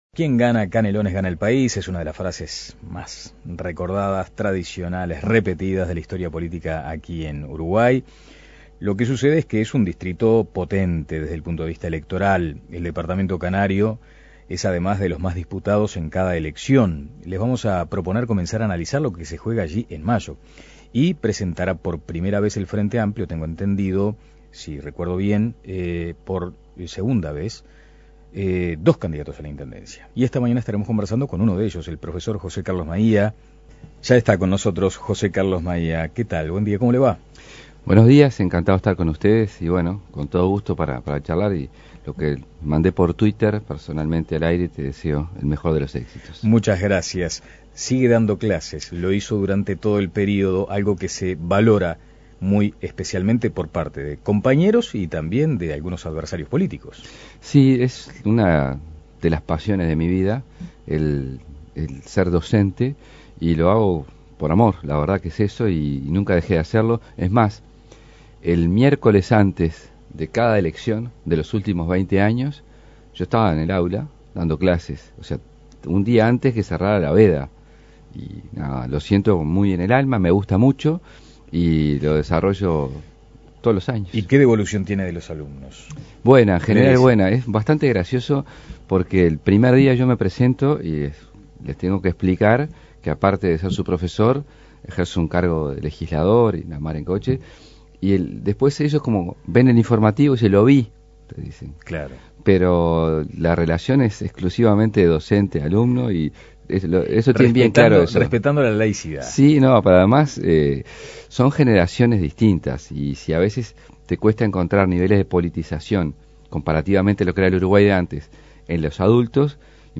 En diálogo con El Espectador, el candidato aseguró que de ser electo trabajará con una concepción "más metropolitana" que deberá involucrar la administración de Montevideo.